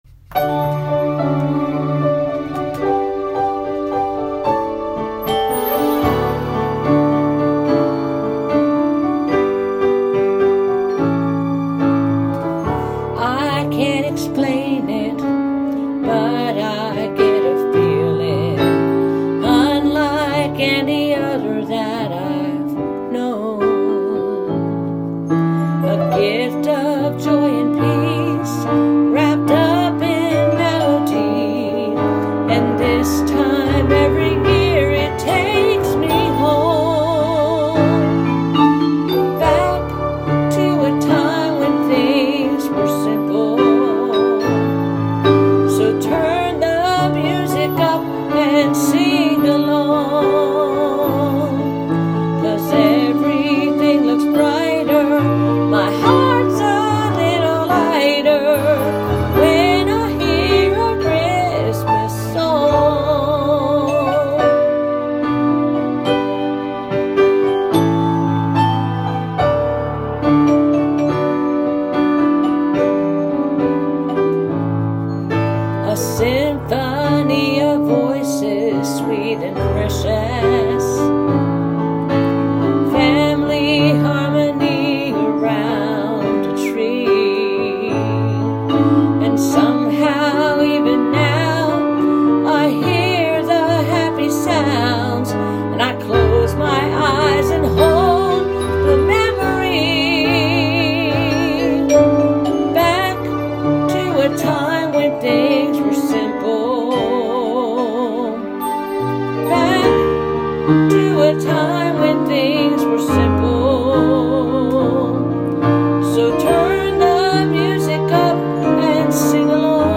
Easy listening–jazz sound